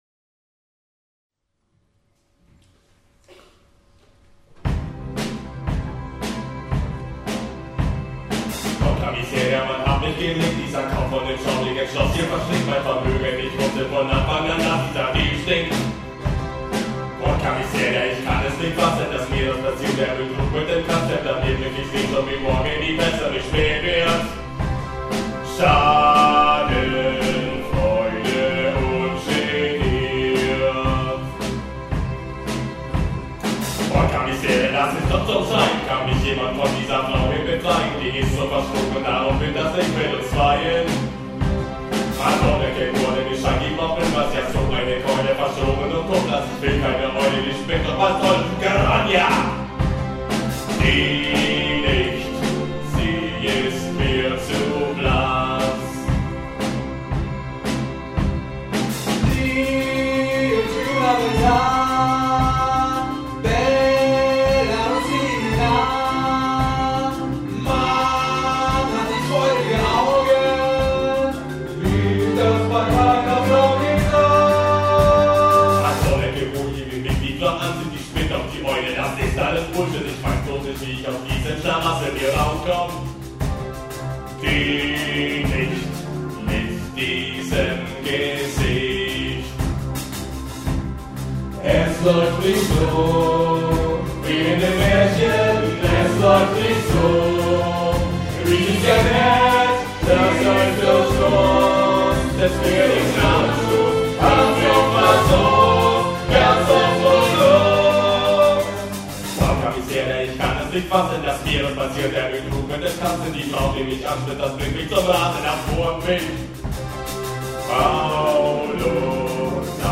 terzett.mp3